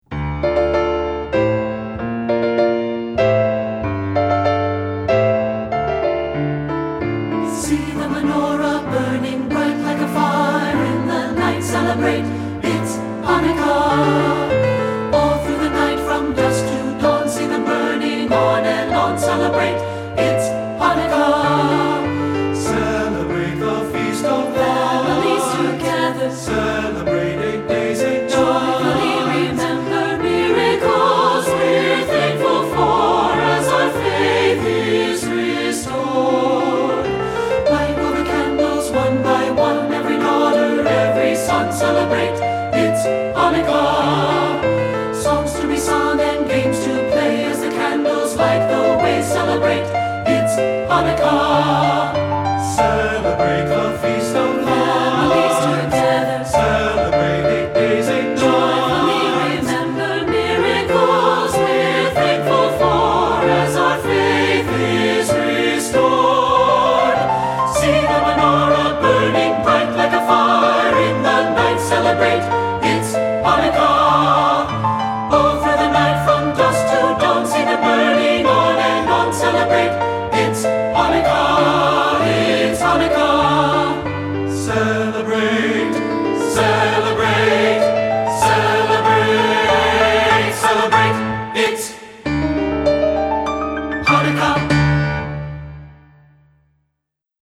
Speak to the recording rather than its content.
Studio Recording